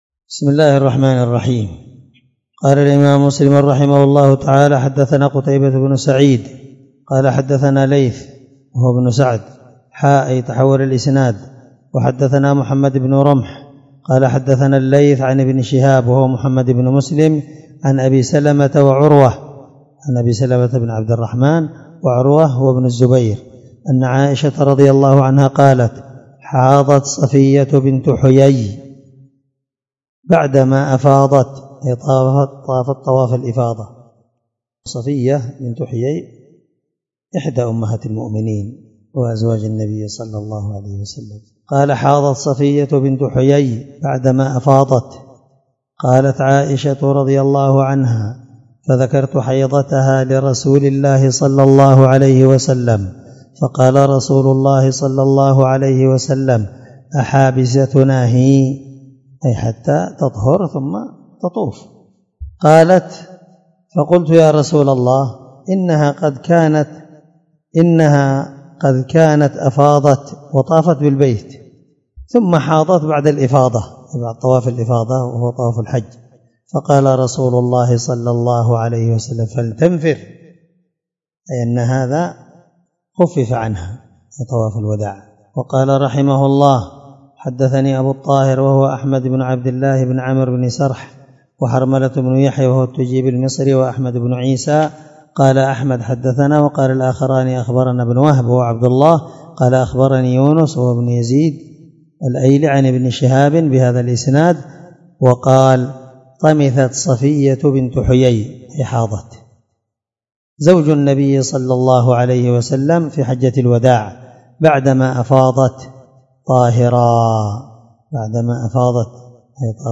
الدرس64من شرح كتاب الحج حديث رقم(1211) من صحيح مسلم